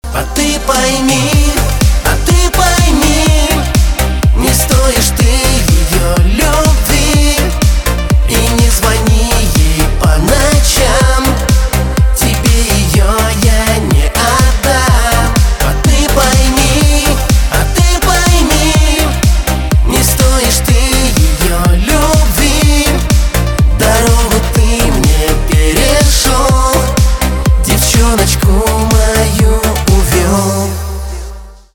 поп
диско
чувственные